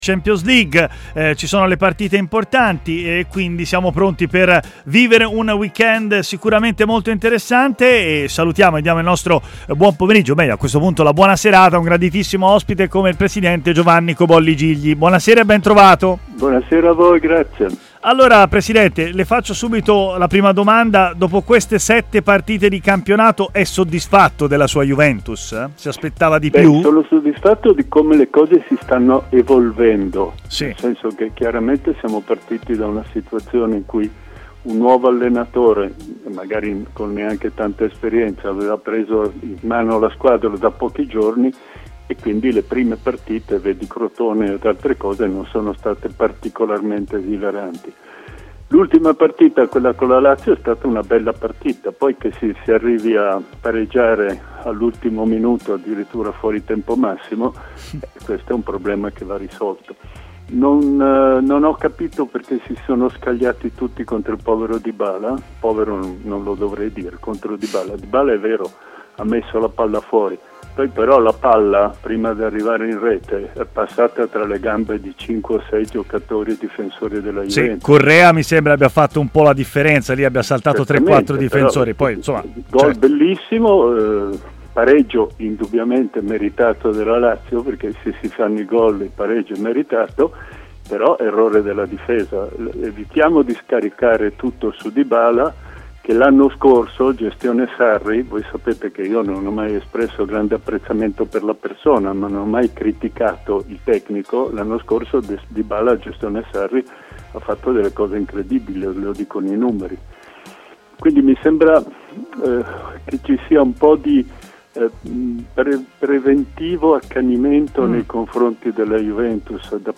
è intervenuto a TMW Radio, nel corso della trasmissione Stadio Aperto